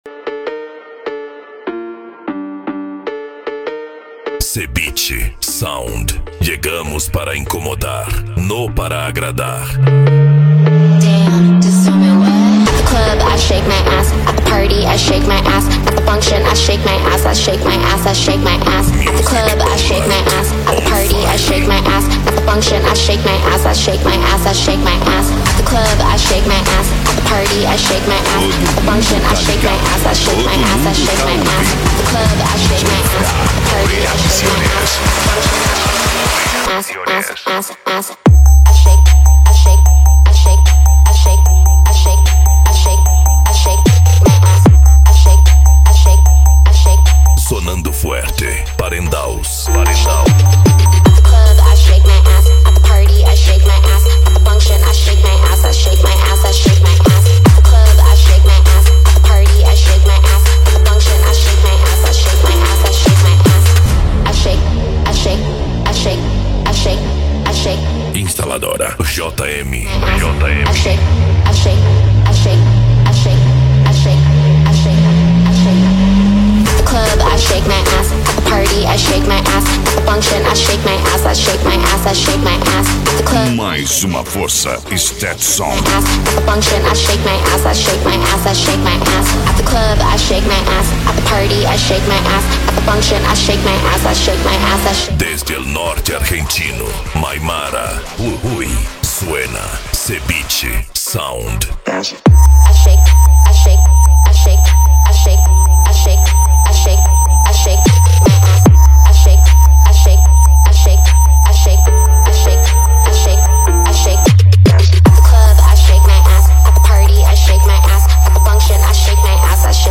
Remix
Racha De Som
Bass